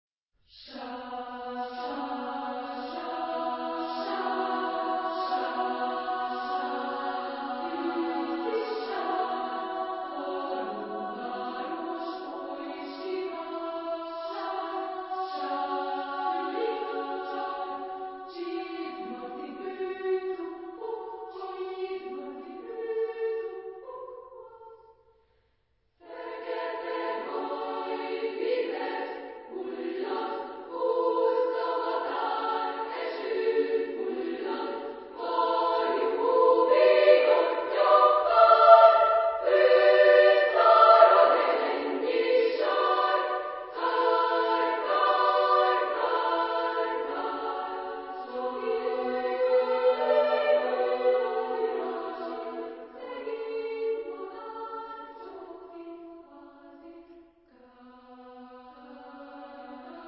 Genre-Stil-Form: Kinder ; Chor ; Zyklus
Chorgattung: SMA  (3 Kinderchor Stimmen )